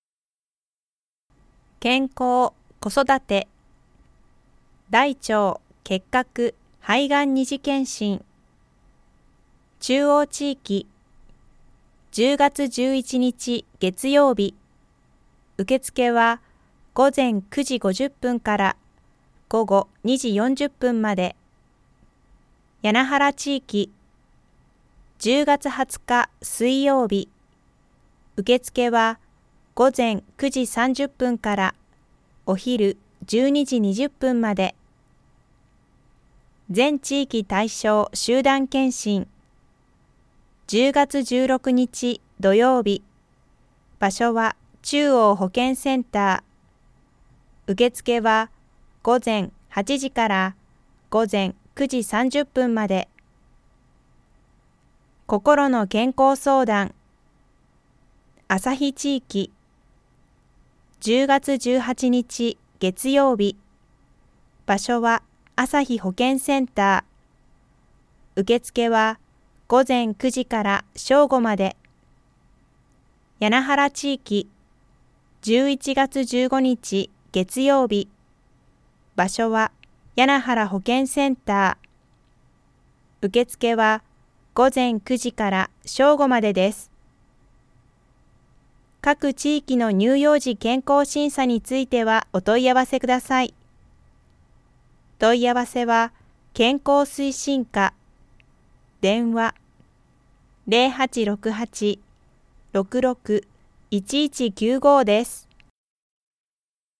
声の広報
広報誌の一部を読み上げています。